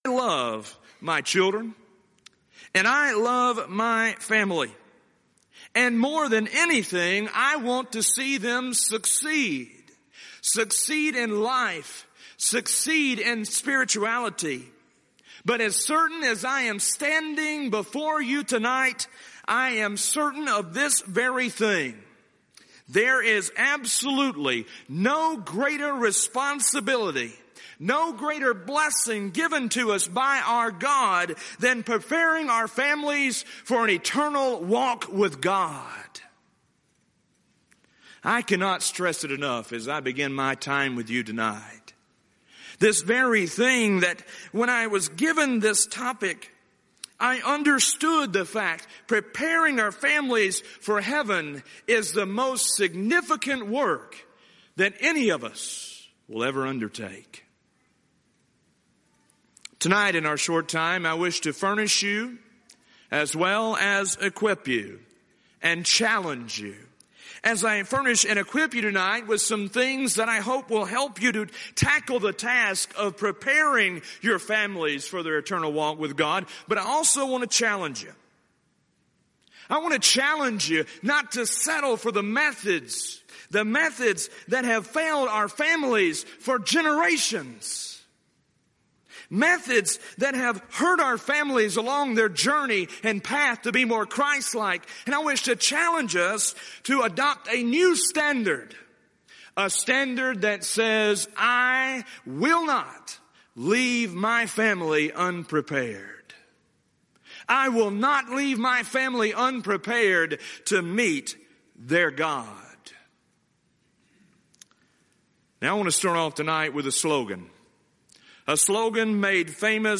Event: 31st Annual Southwest Lectures